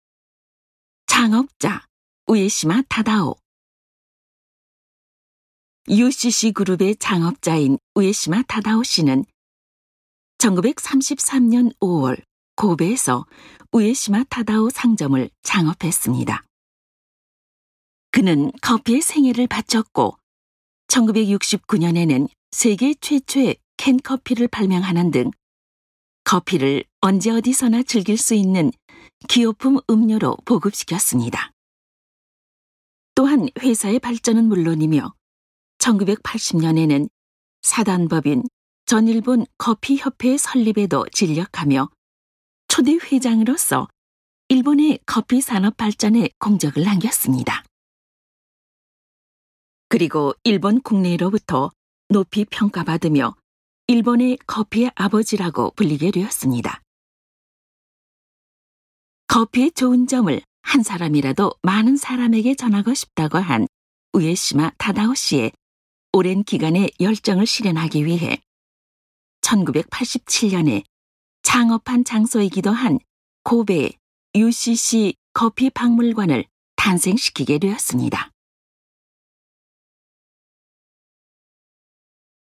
전시 음성 가이드